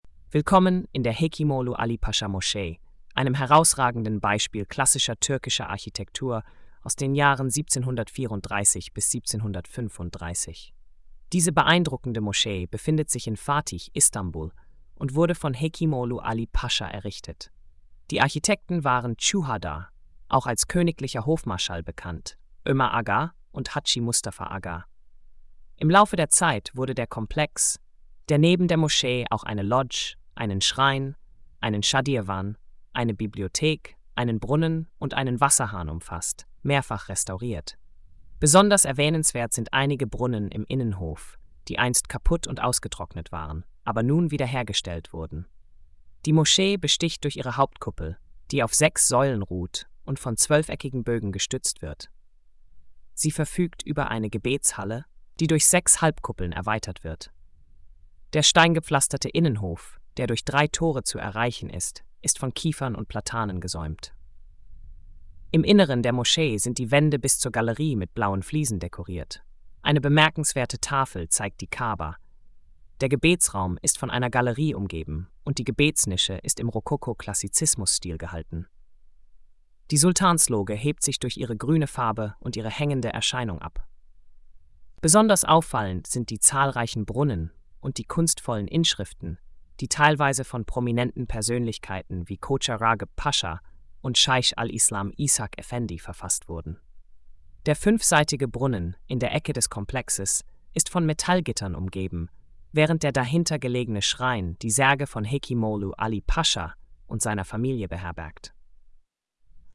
Hörfassung des inhalts: